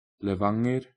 Category:Norwegian pronunciation of cities